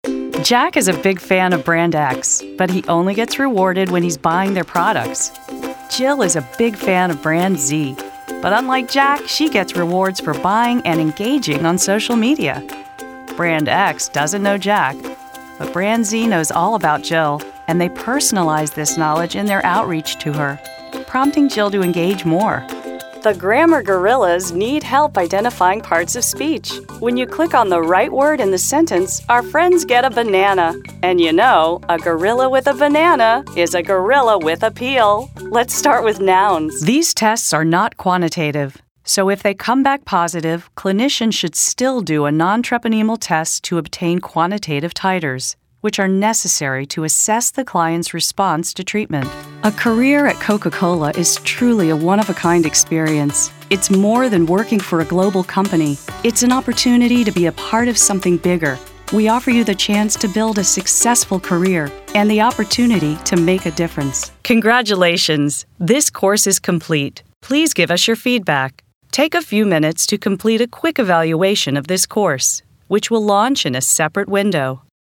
Voiceover
E-Learning Demo